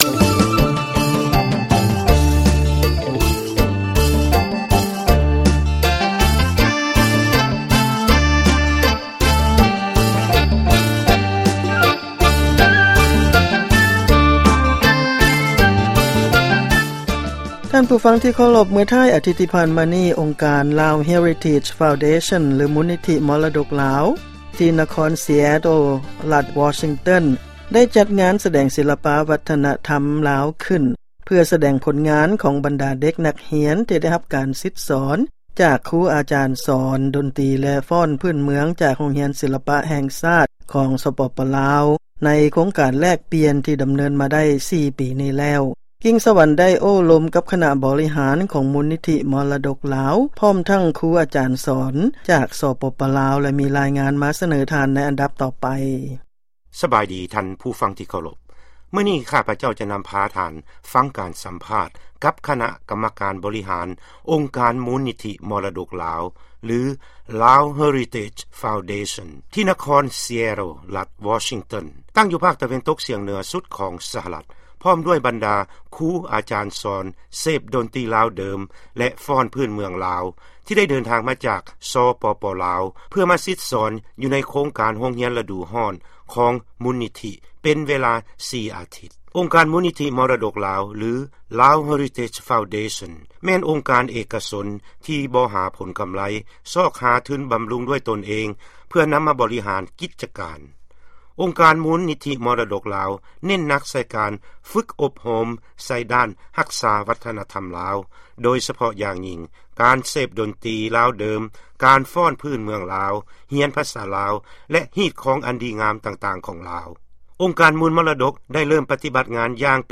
ຟັງລາຍງານ ການສຳພາດ ຄະນະກຳມະການບໍລິຫານ ແລະຄູອາຈານ ອົງການມູນນິທິມໍລະດົກລາວ